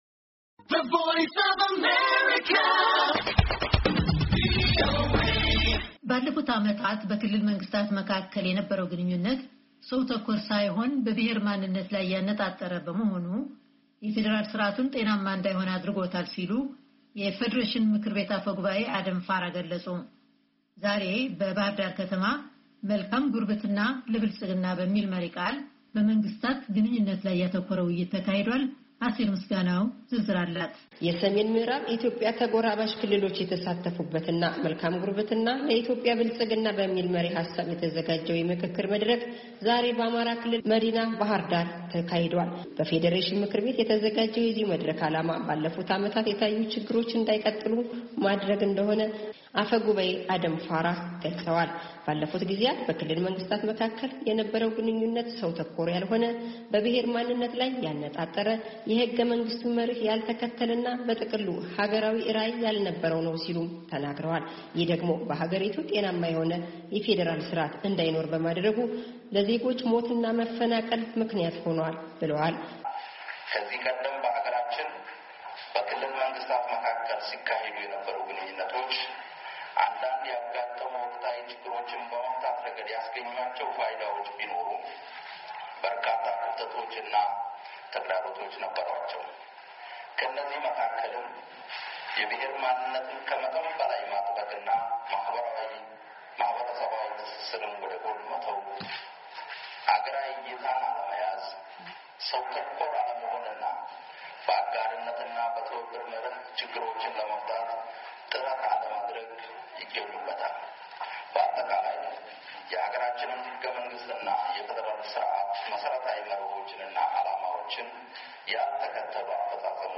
"መልካም ጉርብትና ለኢትዮጵያ ብልፅግና" የውይይት መድረክ በባህር ዳር